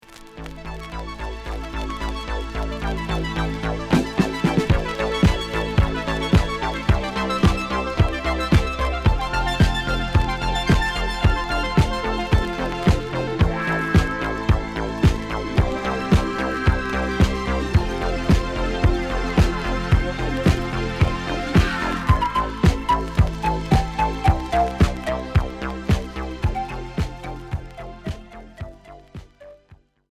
New wave electro Unique 45t retour à l'accueil